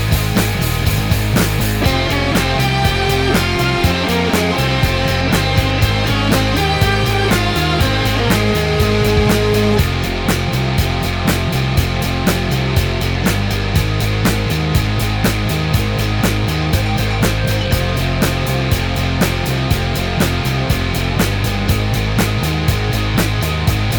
no Backing Vocals Indie / Alternative 2:48 Buy £1.50